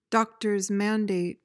PRONUNCIATION:
(DOK-tuhrz MAN-dayt)